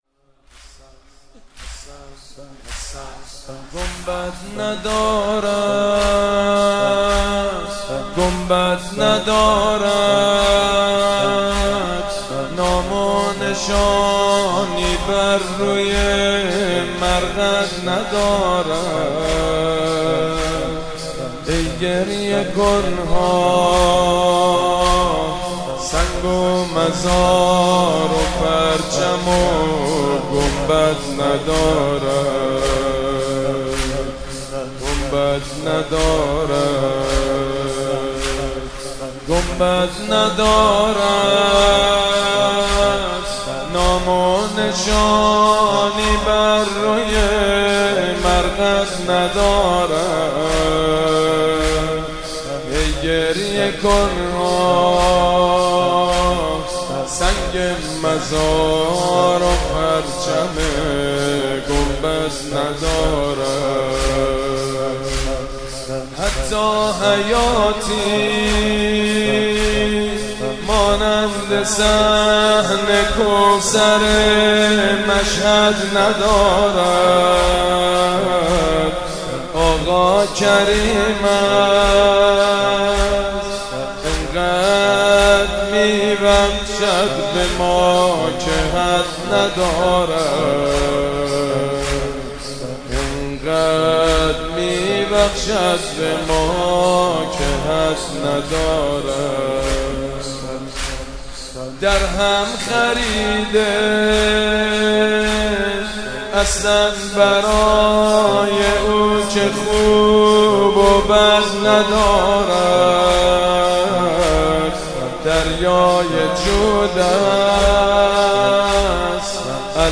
«ویژه مناسبت تخریب بقیع» زمینه: گنبد ندارد، گنبد ندارد